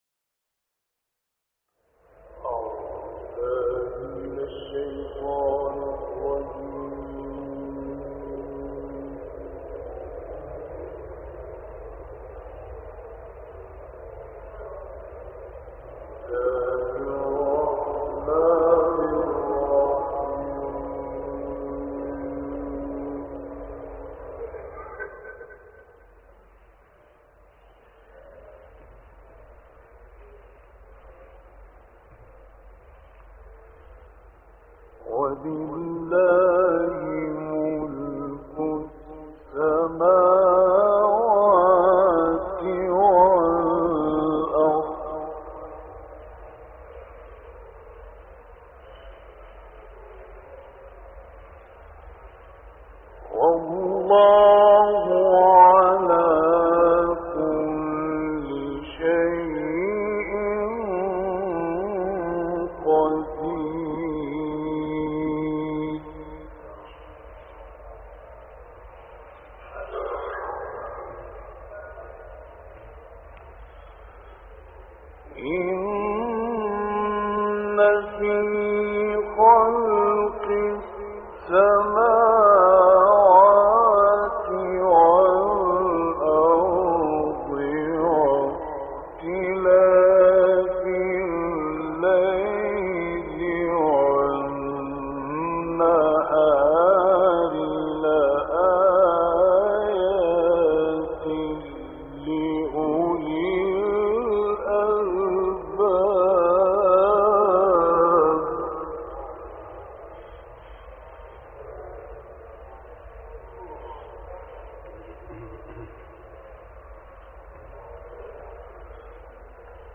دانلود قرائت سوره آل عمران آیات 189 تا 195 - استاد راغب مصطفی غلوش